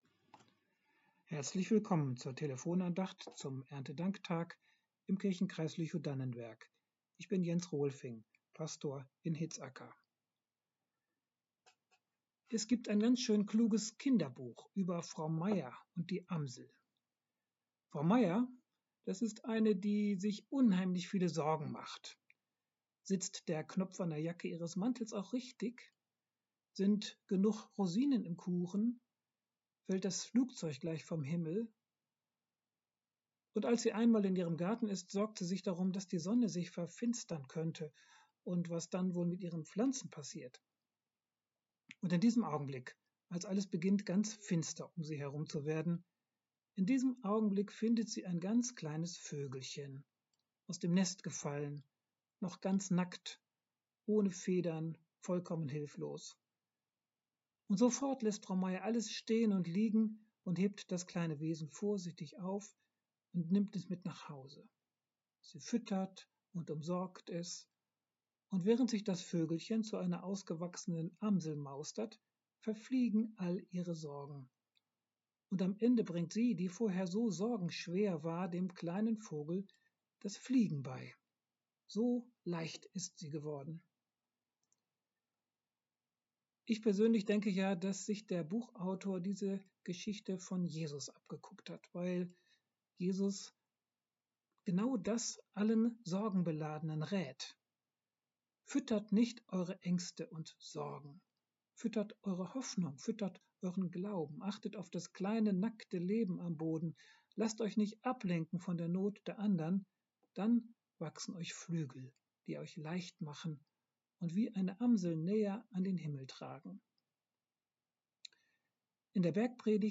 Sorget nicht ~ Telefon-Andachten des ev.-luth. Kirchenkreises Lüchow-Dannenberg Podcast